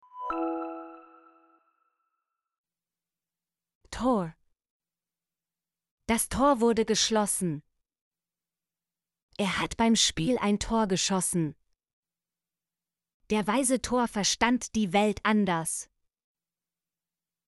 tor - Example Sentences & Pronunciation, German Frequency List